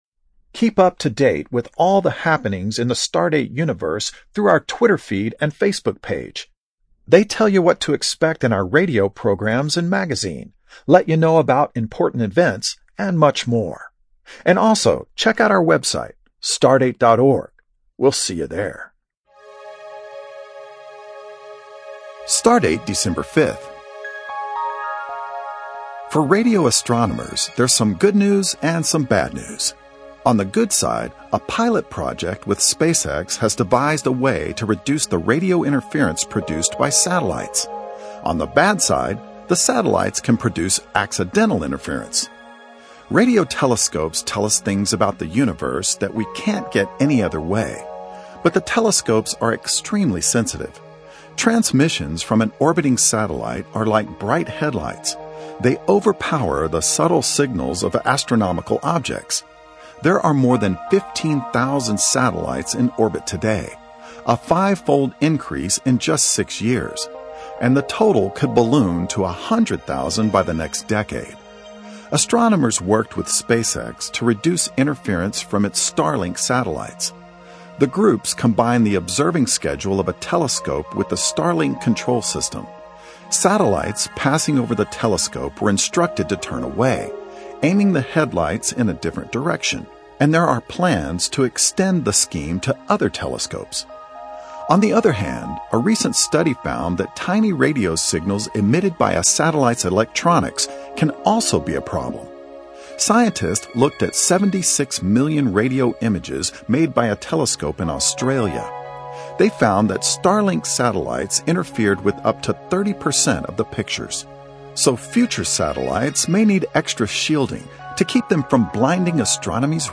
StarDate, the longest-running national radio science feature in the U.S., tells listeners what to look for in the night sky.